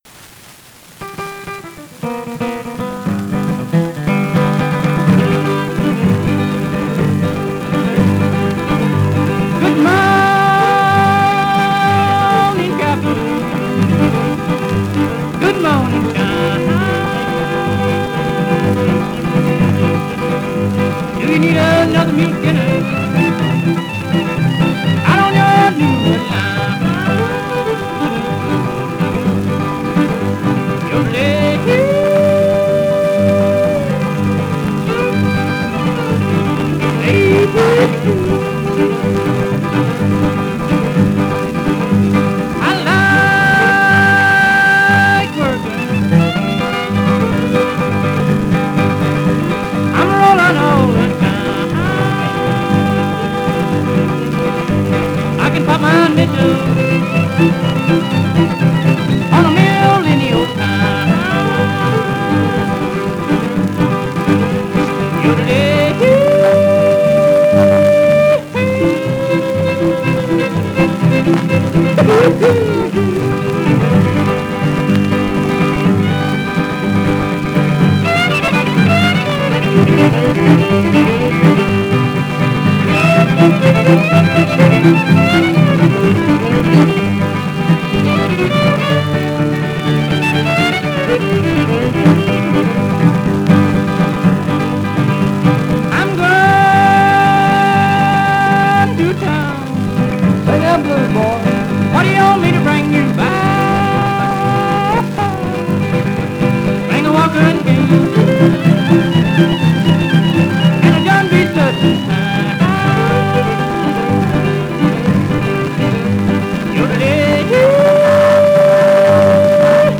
mandolin and guitar
fiddle
string bass